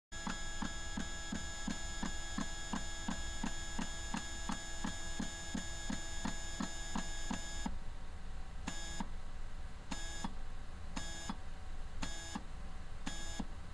The Pacman sound made by the extended range scanning stepper motor.